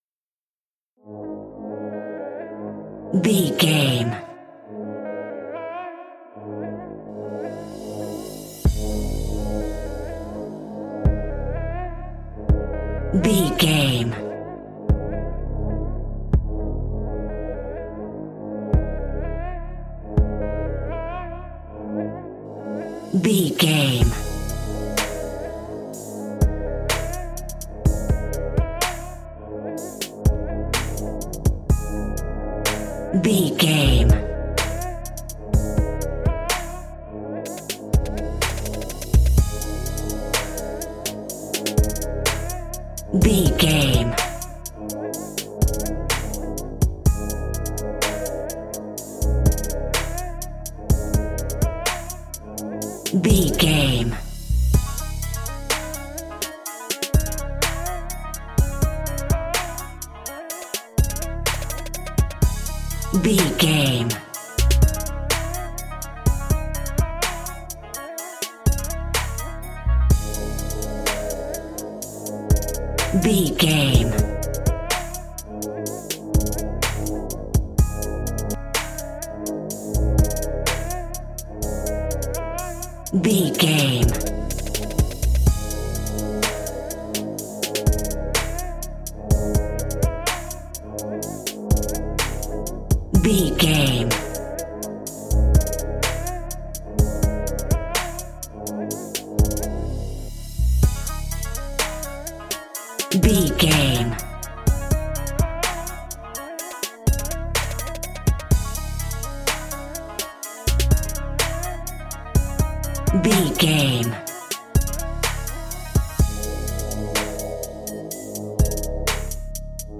Aeolian/Minor
G#
hip hop instrumentals
chilled
laid back
Deep
hip hop drums
hip hop synths
piano
hip hop pads